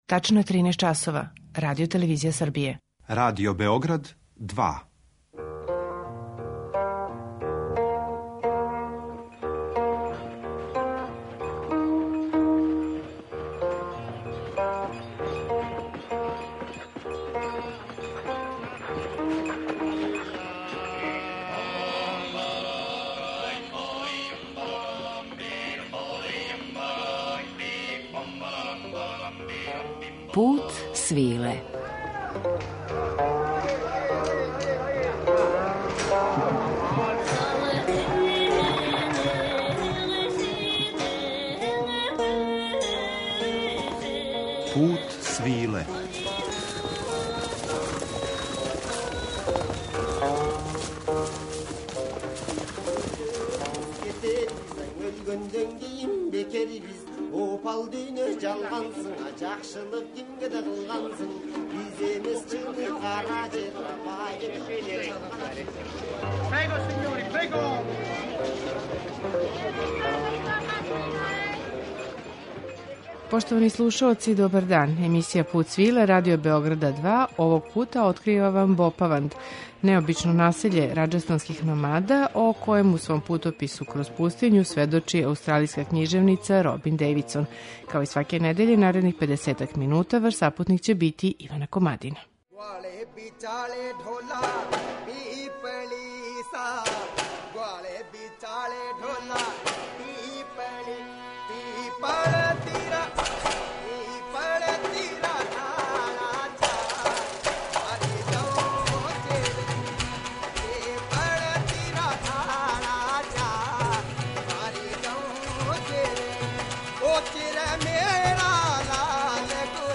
У данашњем Путу свиле , завирићемо у ово необично село у пустињском делу Раџастана, у музичком друштву ансамбла Џипси Банаџарс, који негује музичку традицију номадског народа Банџара из северозападног дела Гуџарата у Раџастану.
Пут свиле, као јединствено “радијско путовање”, недељом одводи слушаоце у неку од земаља повезаних са традиционалним Путем свиле, уз актуелна остварења из жанра “World music” и раритетне записе традиционалне музике.